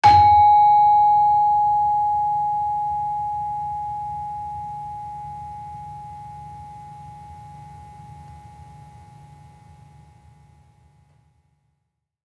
Gender-3-G#4-f.wav